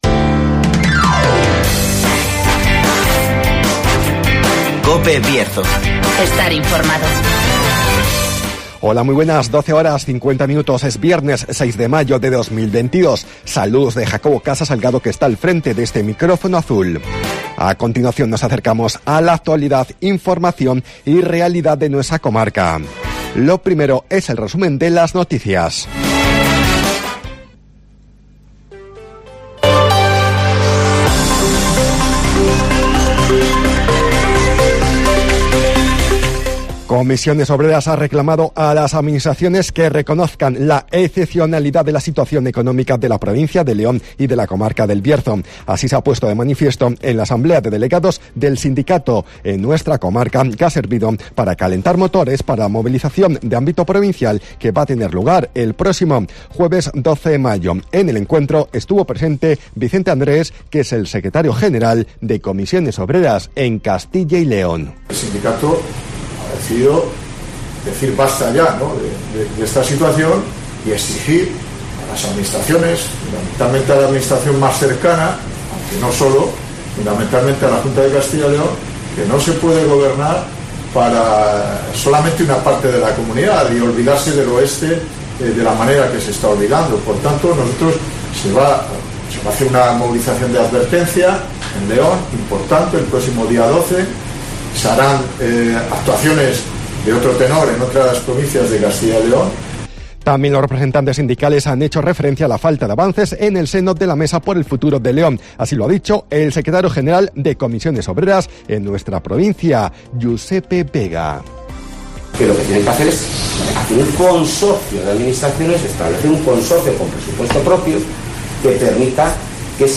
AUDIO: Resumen de las noticias, El Tiempo y Agenda